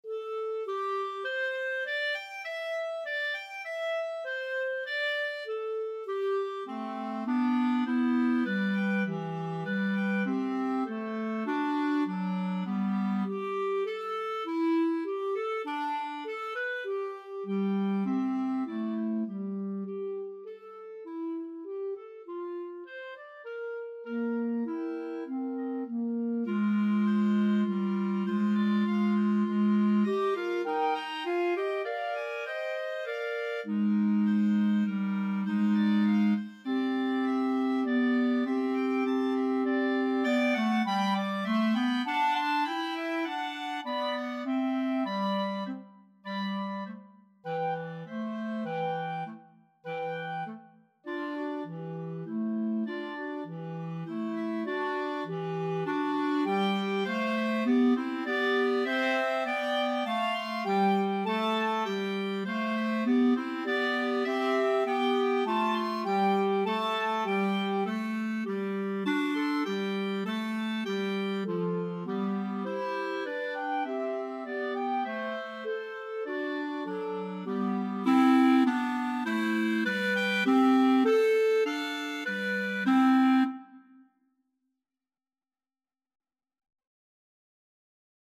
C major (Sounding Pitch) D major (Clarinet in Bb) (View more C major Music for Clarinet Trio )
5/4 (View more 5/4 Music)
Allegro guisto (View more music marked Allegro)
Clarinet Trio  (View more Easy Clarinet Trio Music)
Classical (View more Classical Clarinet Trio Music)